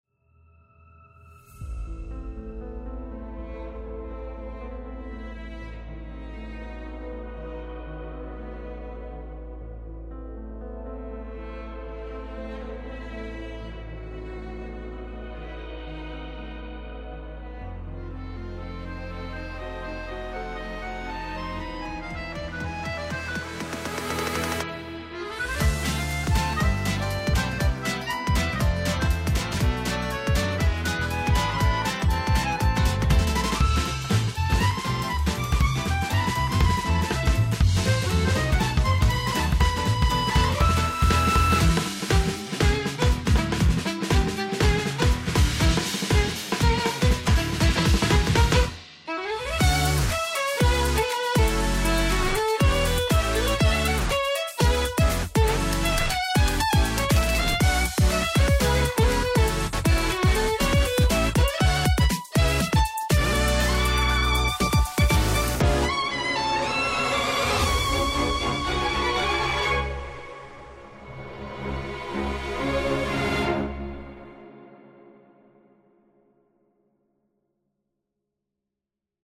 OST Trailer Theme Music BGM